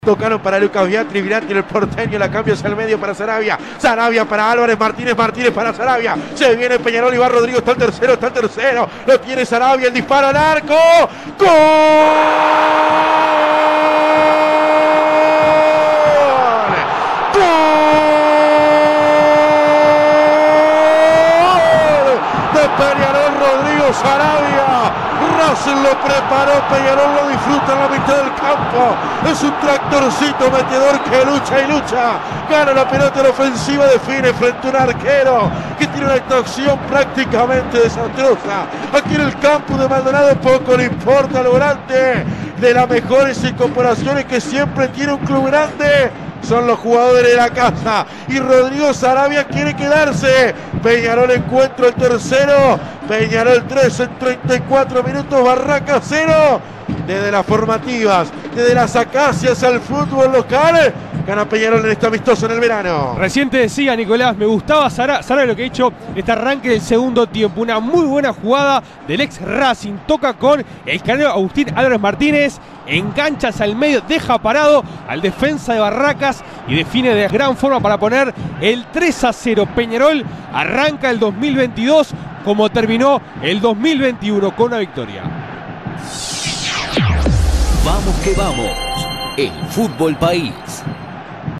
Relato Vamos que vamos: